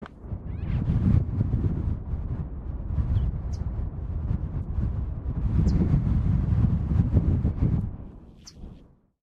Mourning Sierra Finch (Rhopospina fruticeti)
Sex: Male
Life Stage: Adult
Location or protected area: Valle Inferior del Río Chubut (VIRCH)
Condition: Wild
Certainty: Observed, Recorded vocal